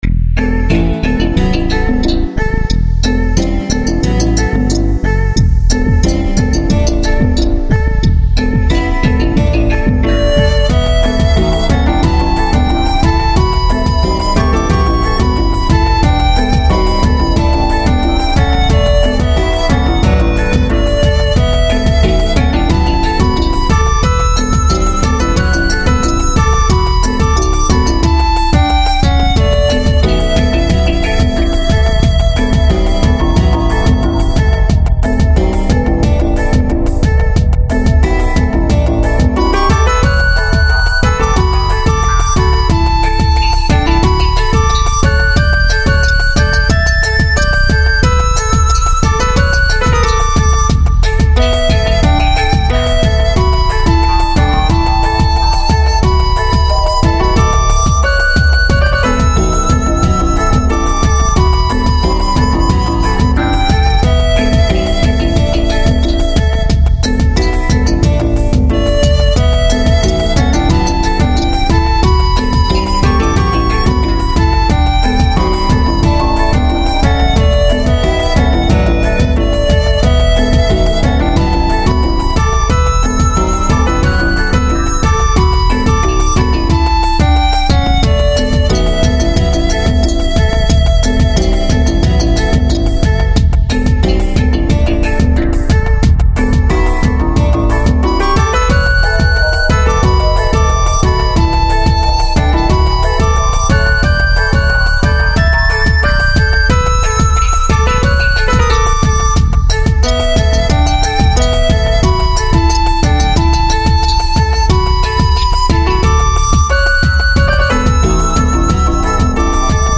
アレンジ
各種ＶＳＴiで外人が作ったＭＩＤＩをイジり倒した。